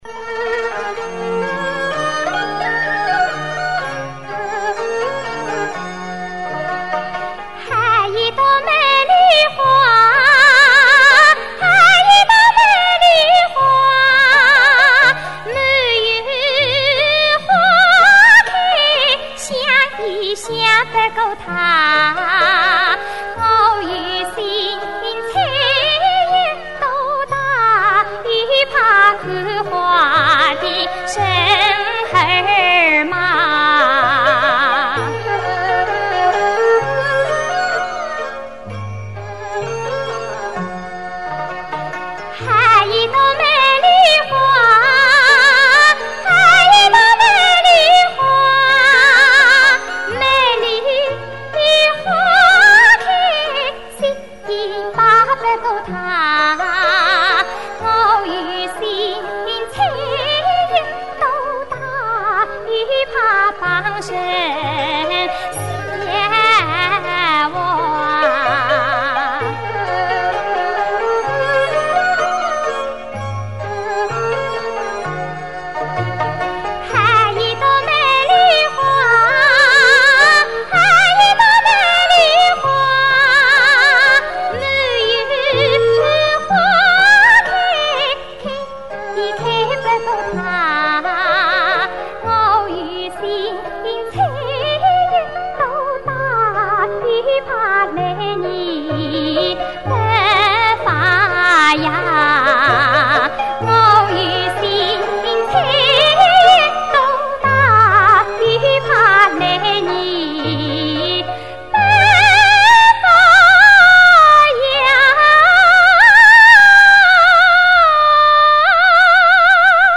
[15/9/2009]原生态系列 江苏原生态民歌 比较地道！